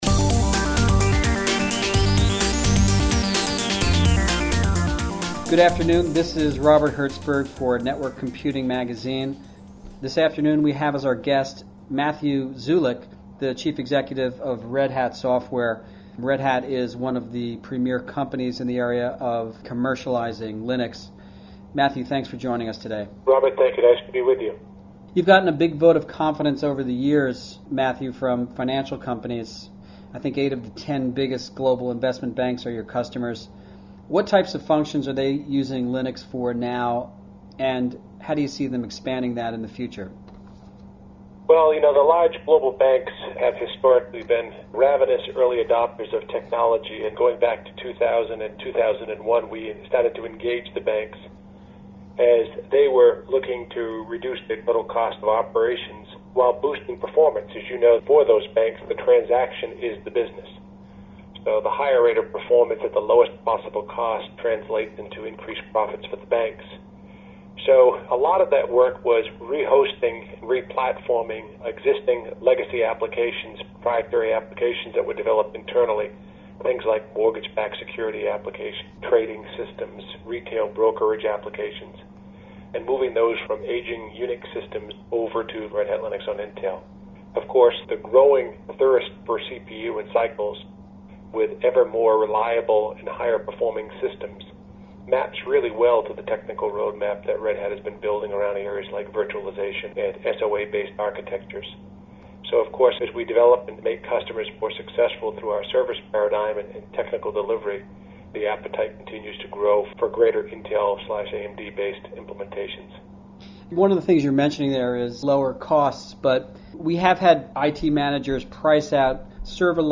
We are stepping back and observing right now--and hopefully becoming a good, attentive listener.• Podcast: right click to download the complete interview